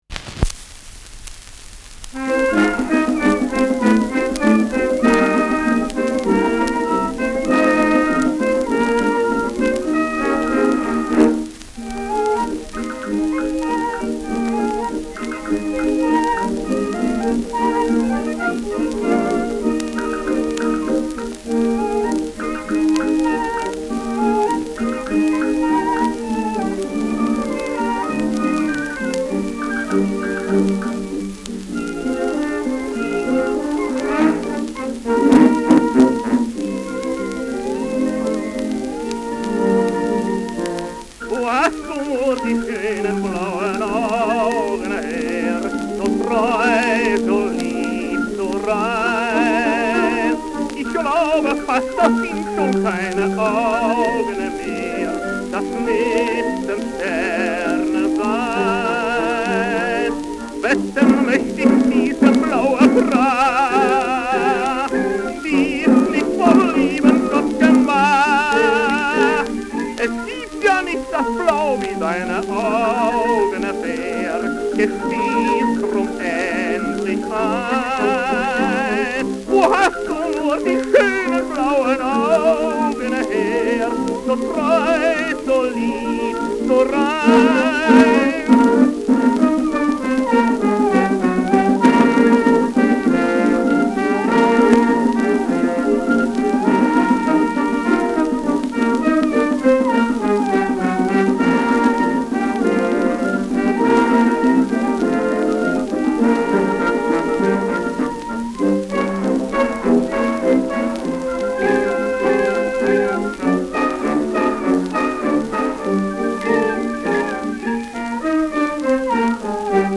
Schellackplatte
Leicht abgespielt : Gelegentlich leichtes Knacken